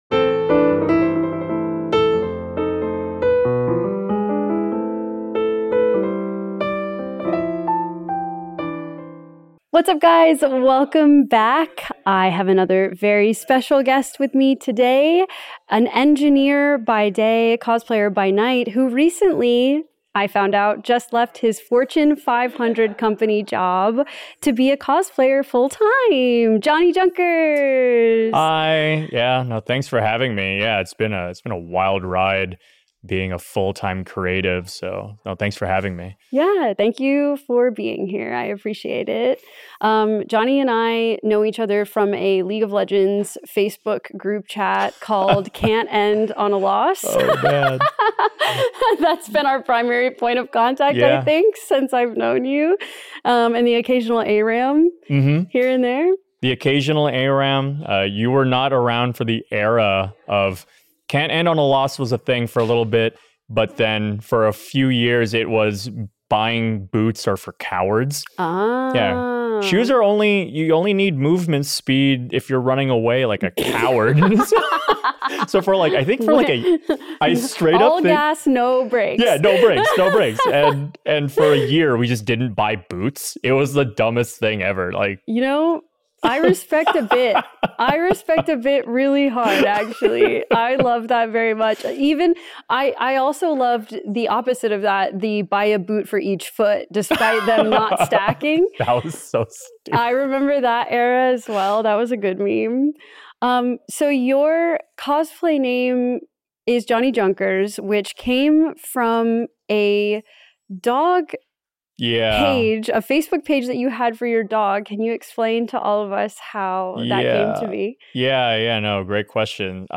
Apologies for the noise.